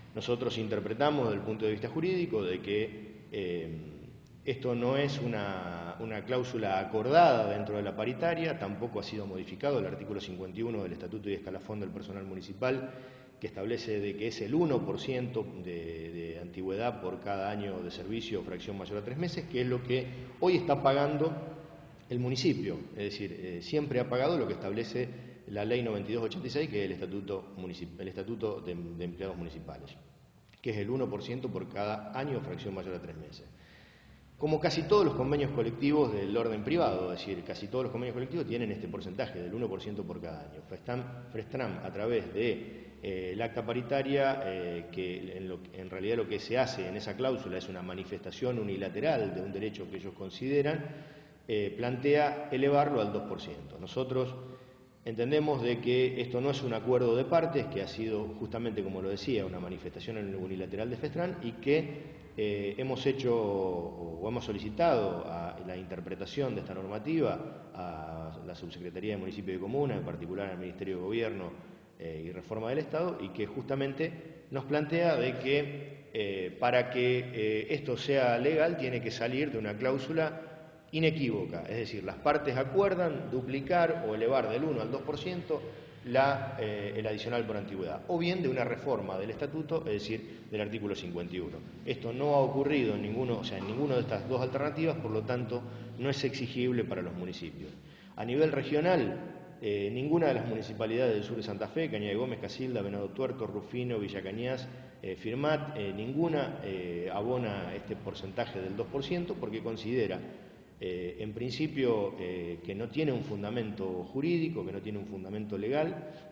En medio del paro que llevan adelante los empleados municipales nucleados en Sitramf, el secretario de Finanzas Municipal, Diego Bullorini, dialogó con Firmat24 al respecto.